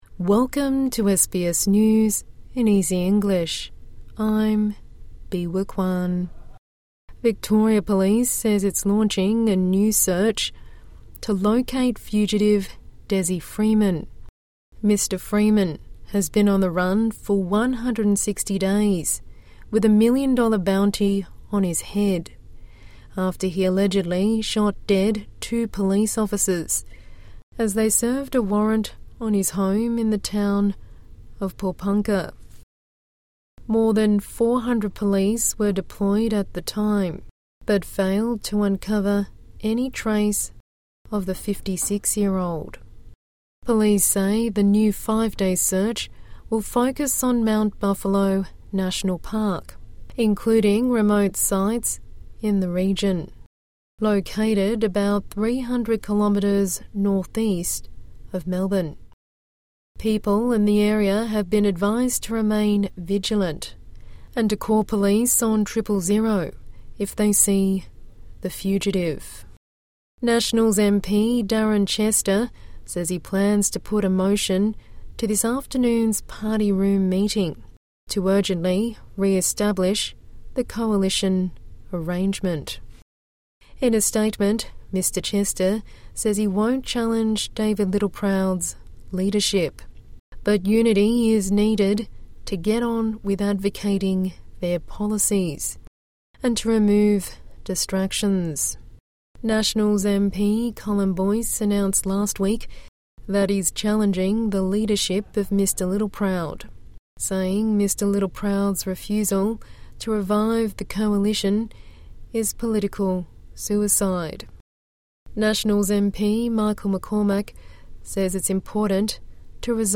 A daily 5-minute news bulletin for English learners and people with a disability.